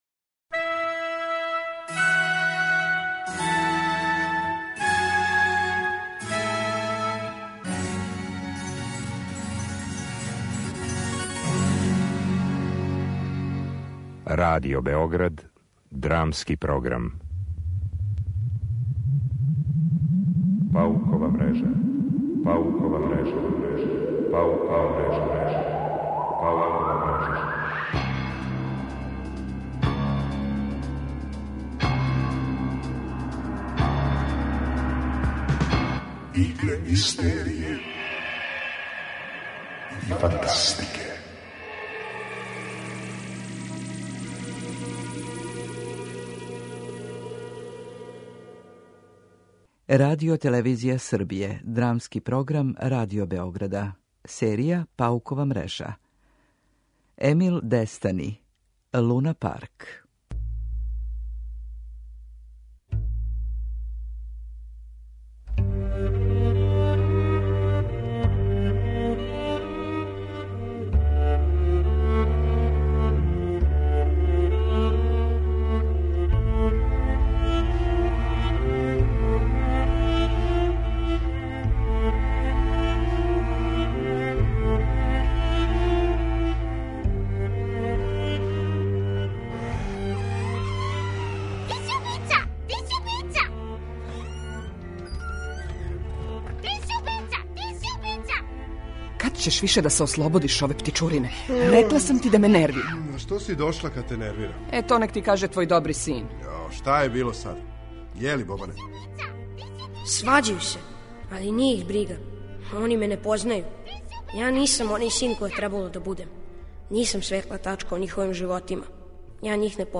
Драмски програм: Паукова мрежа